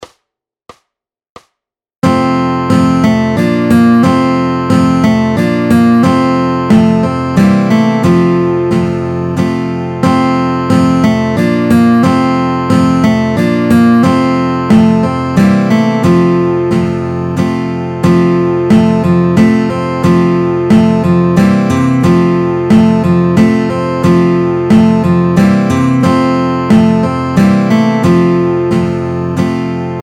Formát Kytarové album
Hudební žánr Vánoční písně, koledy